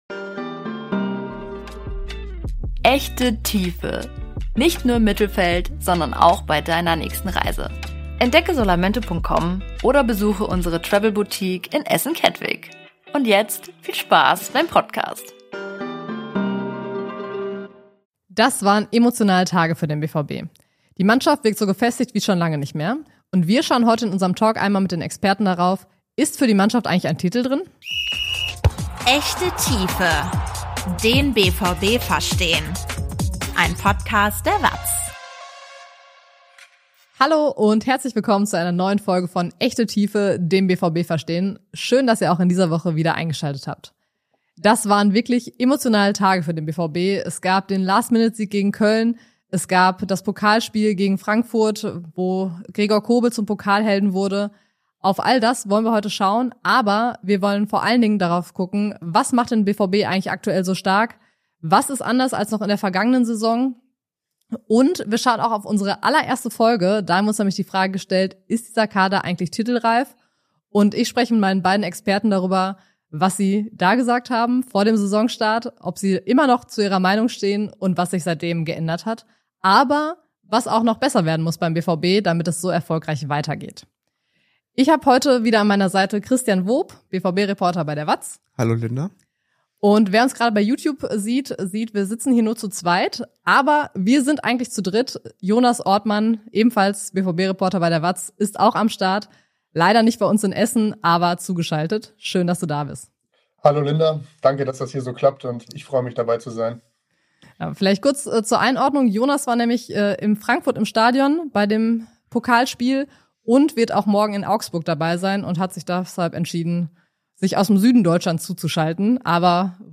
BVB-Talk
BVB-Reporter diskutieren ein Thema in der Tiefe